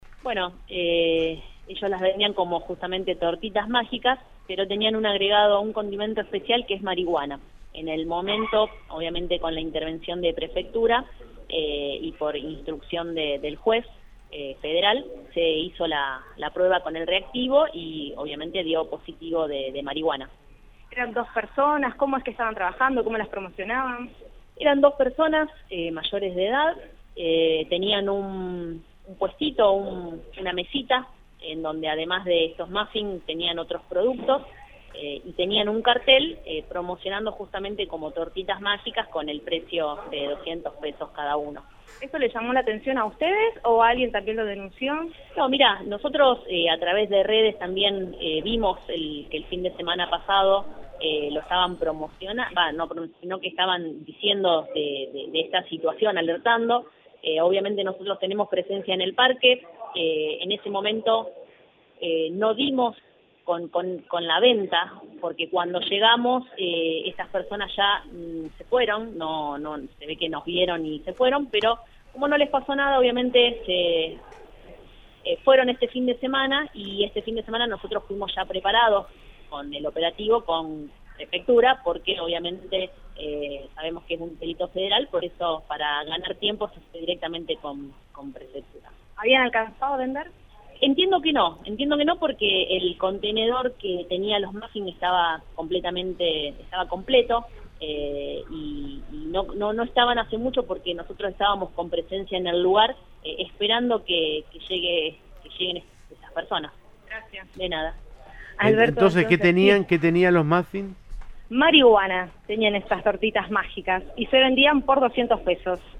En una entrevista con el móvil de Cadena 3 Rosario, en Siempre Juntos, la funcionaria precisó que los detenidos eran dos personas mayores de edad, que tenían “un puestito con muffins y otros productos”.